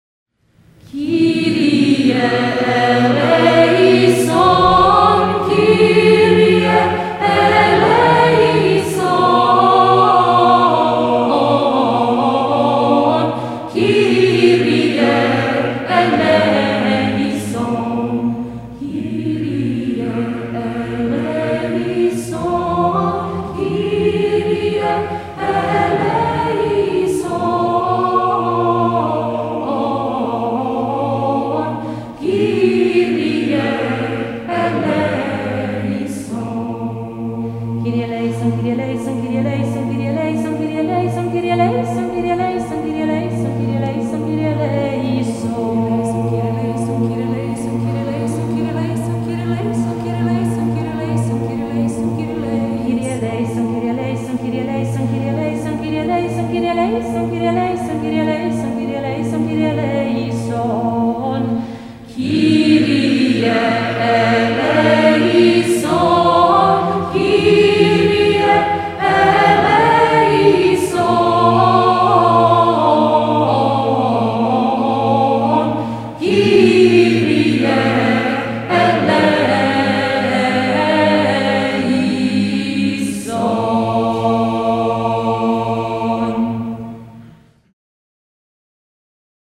"Кирие элеисон" в исполнении Дивны Любоевич
Молитва "Господи помилуй" на греческом языке в исполнении сербской певицы